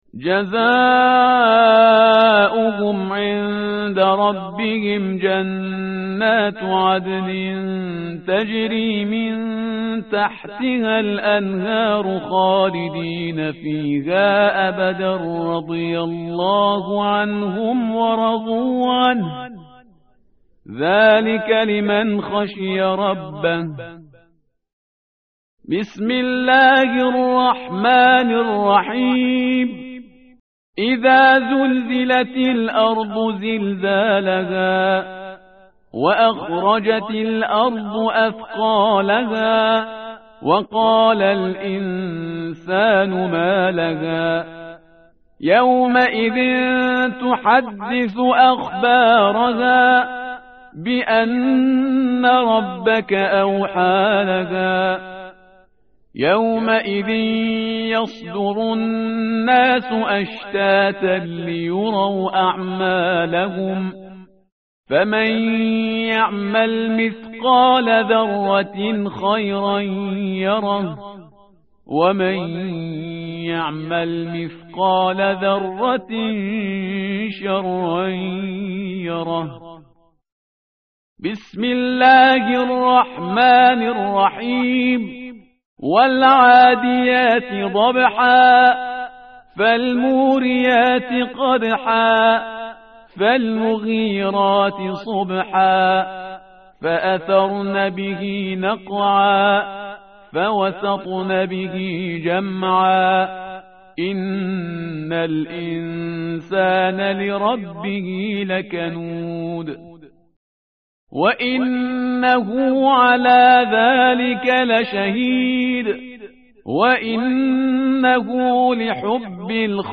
متن قرآن همراه باتلاوت قرآن و ترجمه
tartil_parhizgar_page_599.mp3